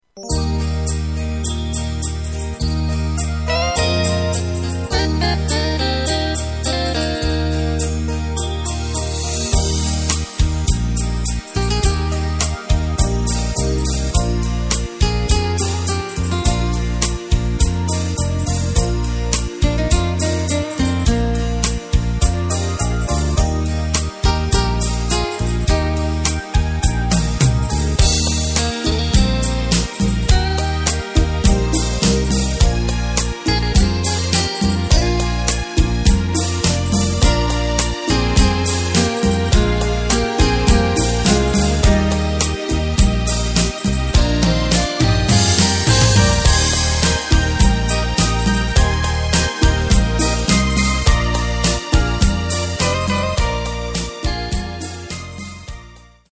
Takt:          4/4
Tempo:         120.00
Tonart:            Eb
Schlager aus dem Jahr 2007!
Playback mp3 mit Lyrics